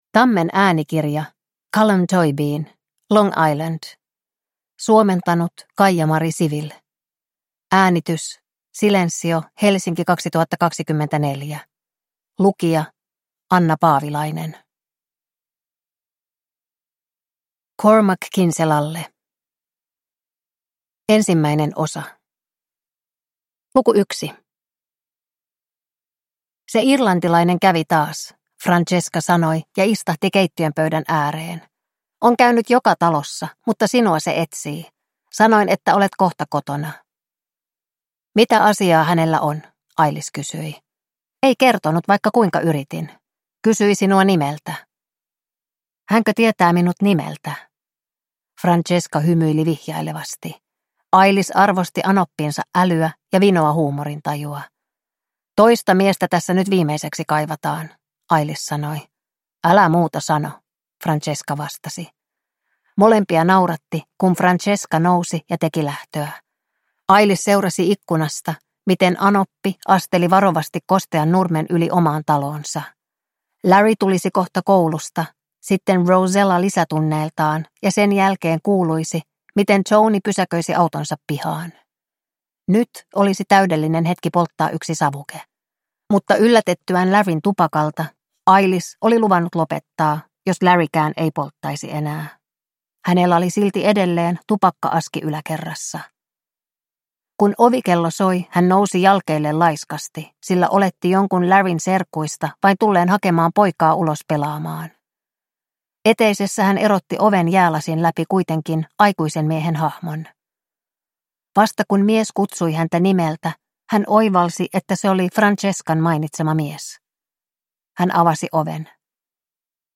Long Island – Ljudbok